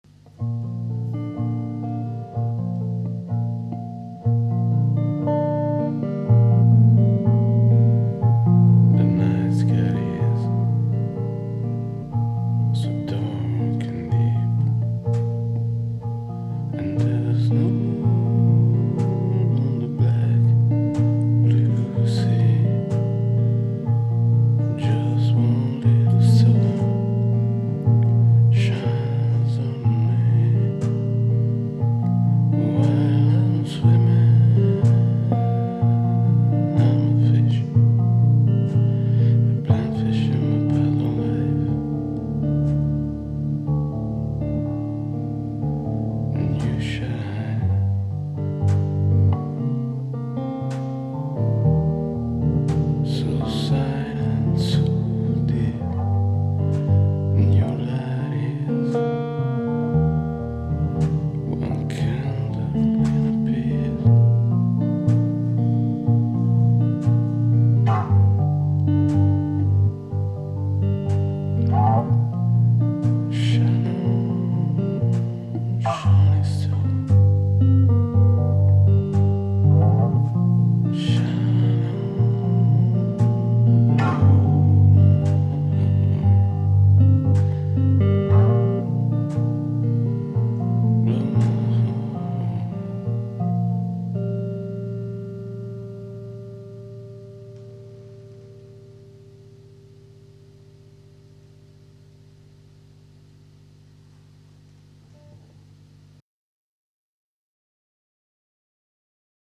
stereo
Live recorded
drums